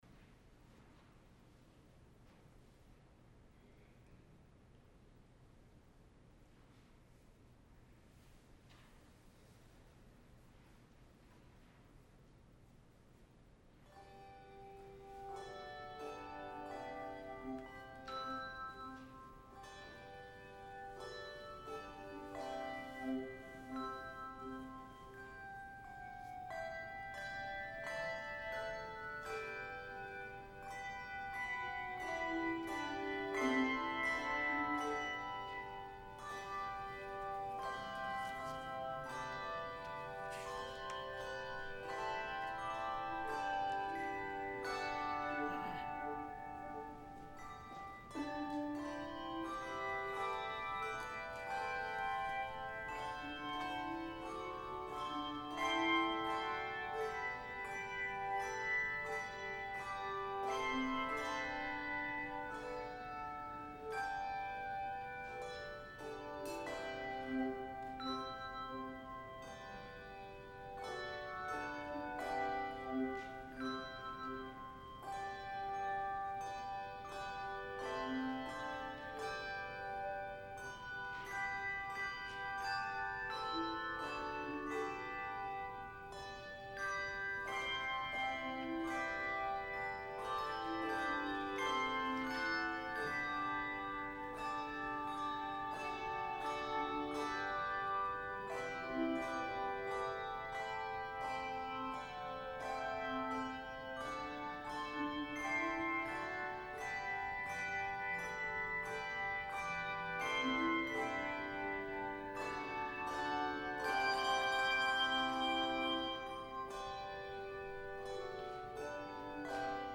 Sunday Worship 2-14-21 (The Transfiguration of Our Lord)